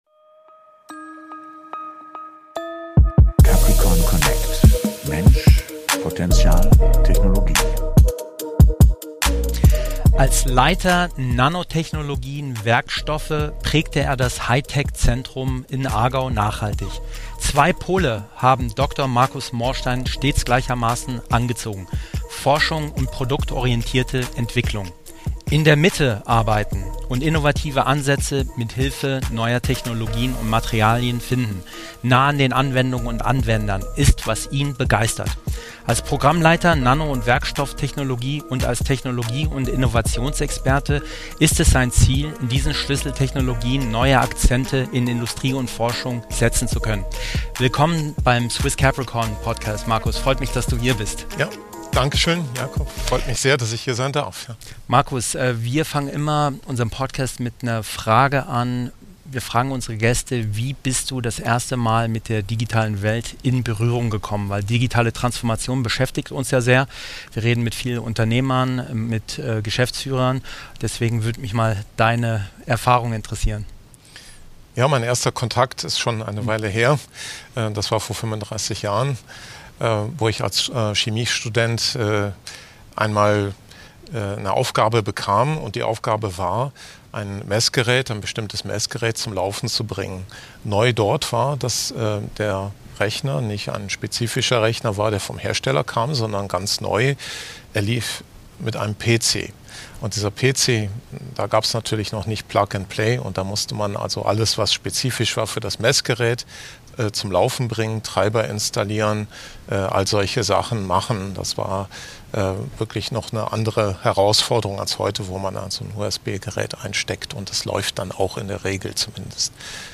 #26 - Interview